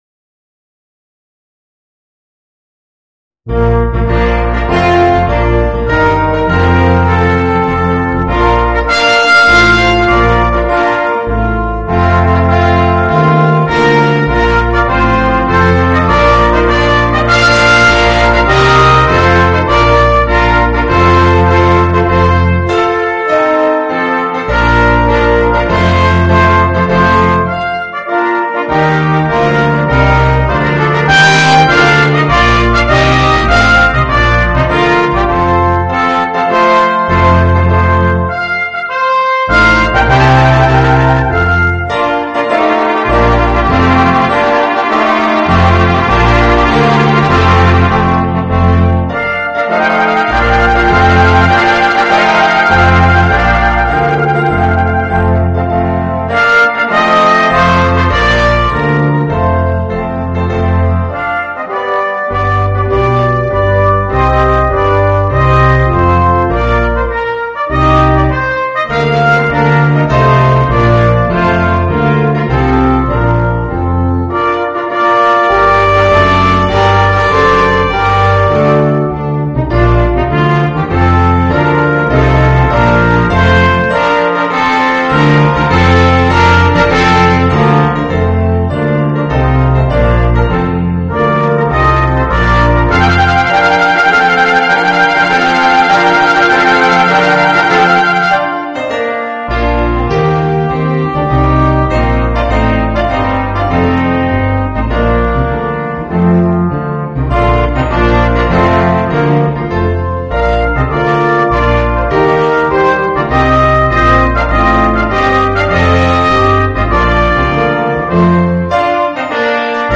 Voicing: 2 Trumpets, Horn, Trombone, Tuba and Piano